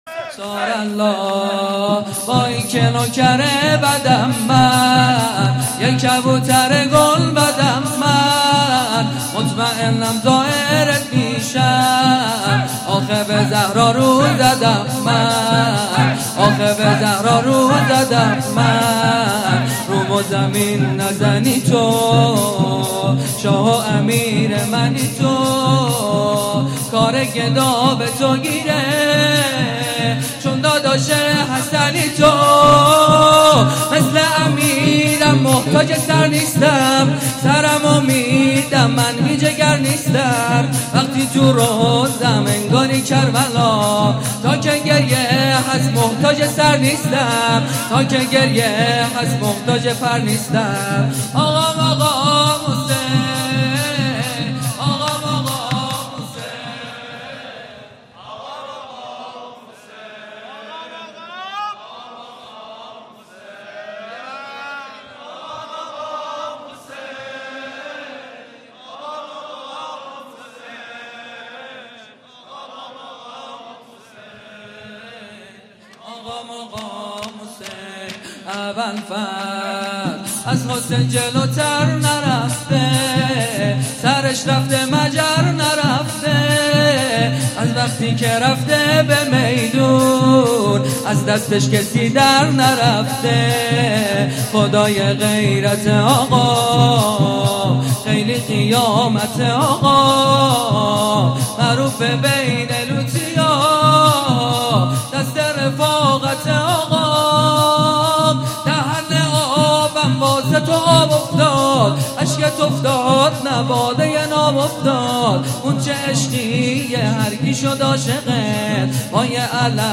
خیمه گاه - هیأت جوانان فاطمیون همدان - شب پنجم (شور)
مسجد شهید مدرس